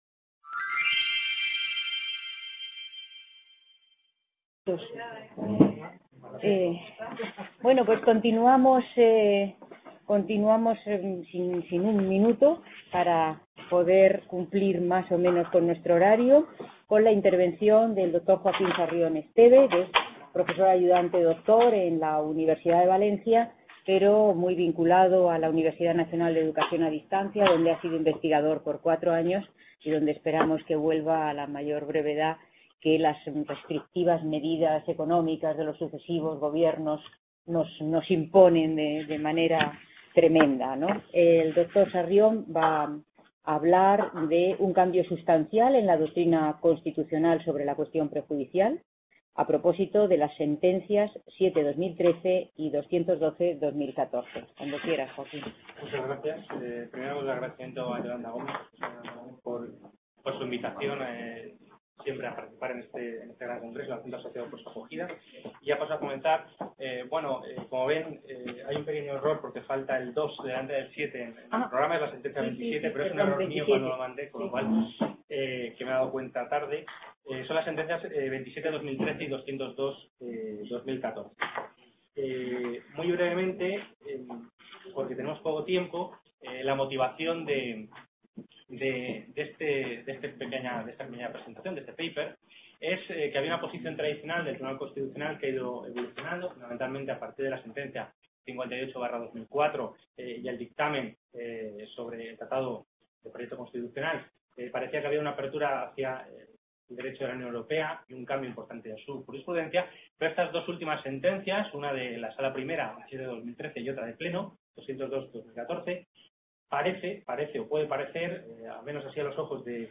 Description C.A. Bruselas - IV Congreso Internacional sobre Unión Europea: nuevos problemas, nuevas soluciones.